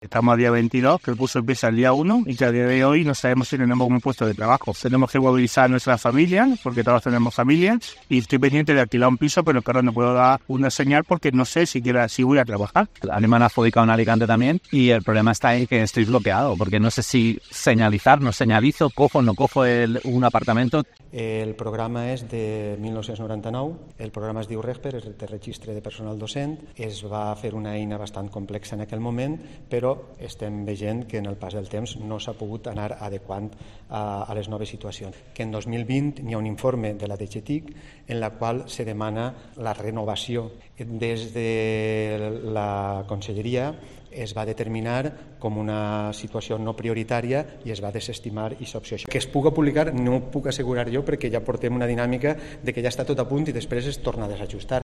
Declaraciones docentes y José Pascual Fernández, Subsecretario General de Personal